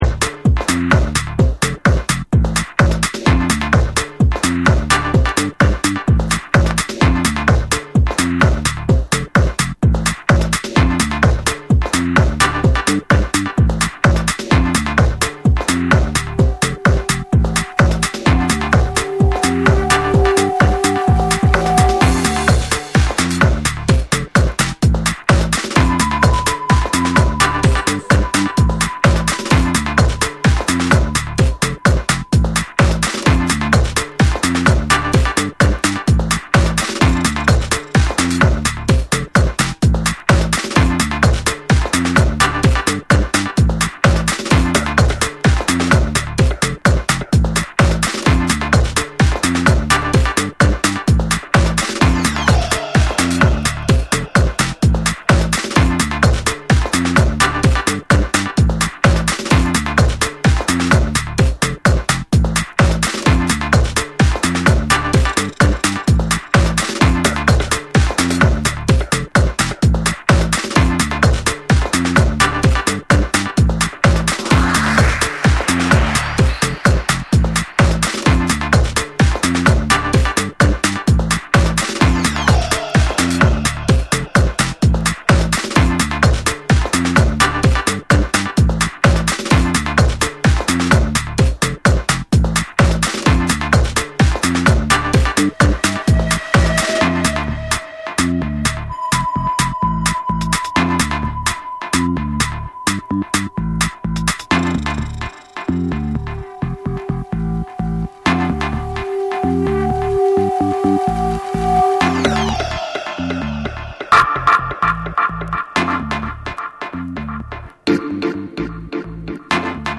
TECHNO & HOUSE